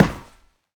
RunMetal6.ogg